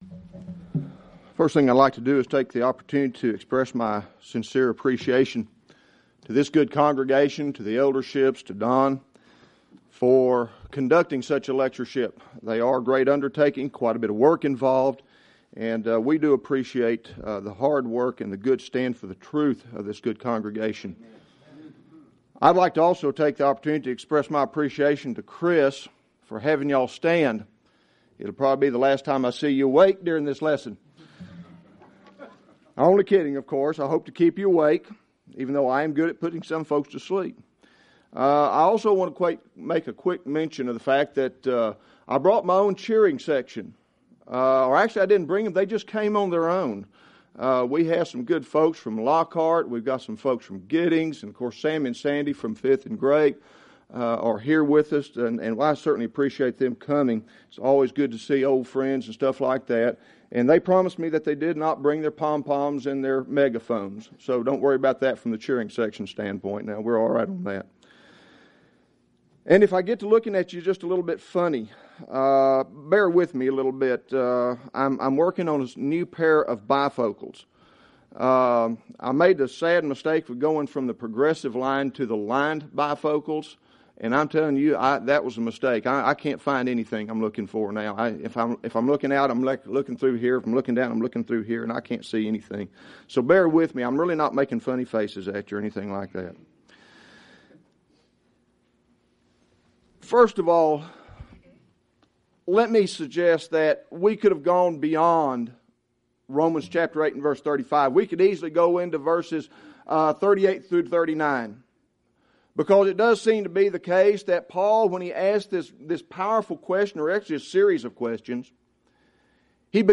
Series: Shenandoah Lectures Event: 2003 Annual Shenandoah Lectures